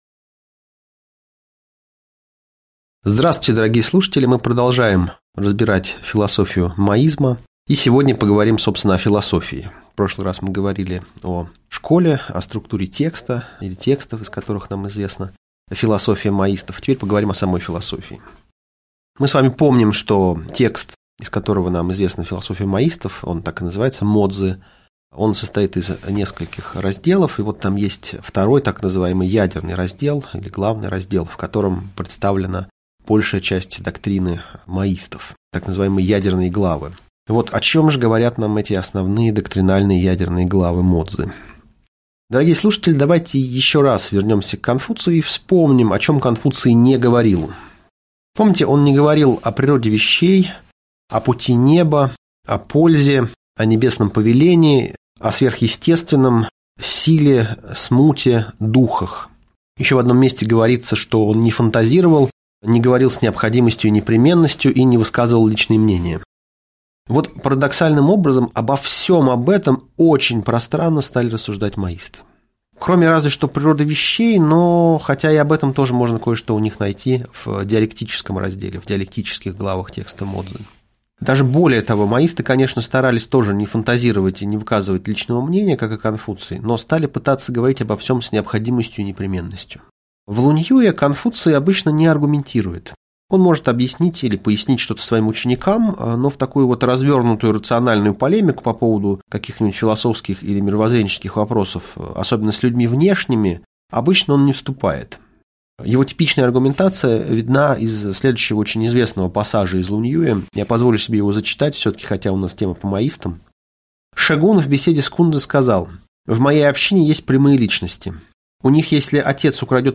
Аудиокнига Лекция «Мо-цзы и моисты. Часть 2»